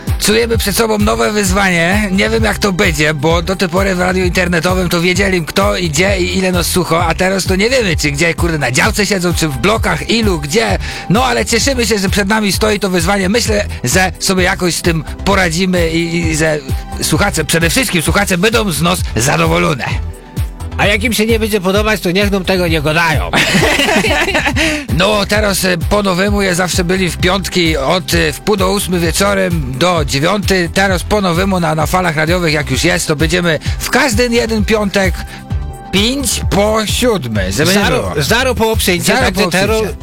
Trzy lata temu na radiowej antenie można było usłyszeć rozmowy z pracownikami radia, którzy opowiadali o swoich audycjach.